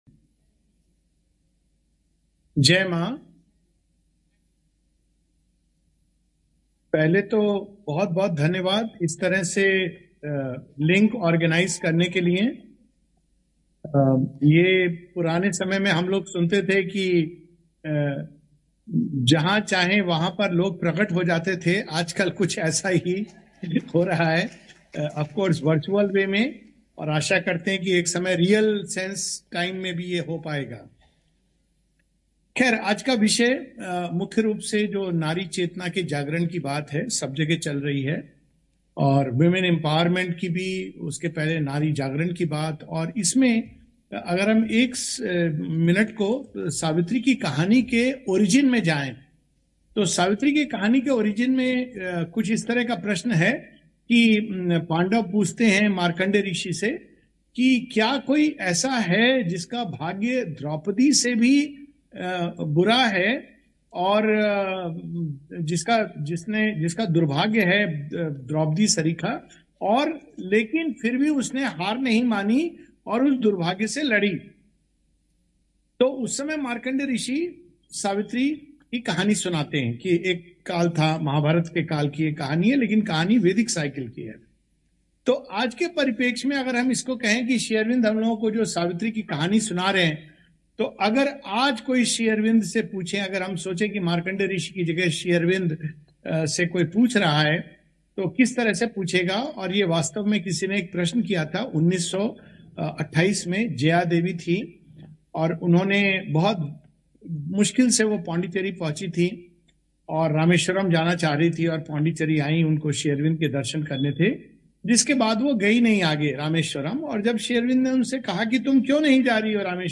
[Savitri as the Ideal Woman] A brief address
to the 4th Sri Aurobindo Women Study Circle (Mahila Pathchakra).